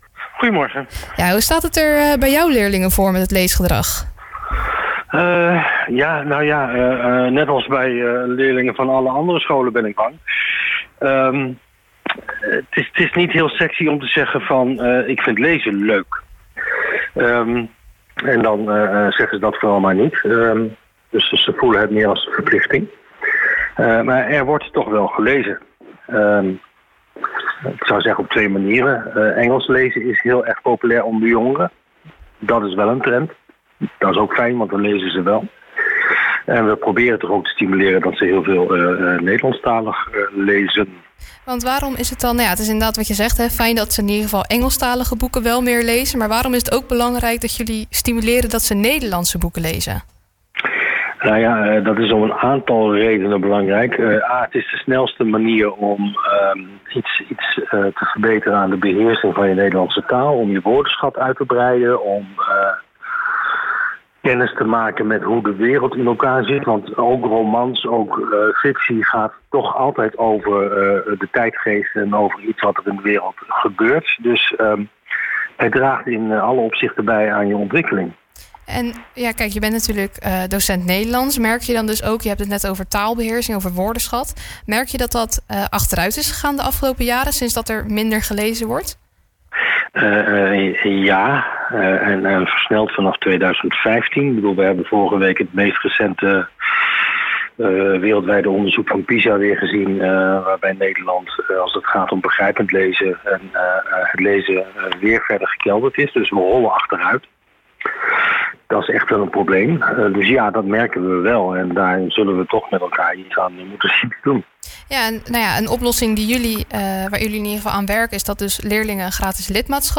gaat erover in gesprek met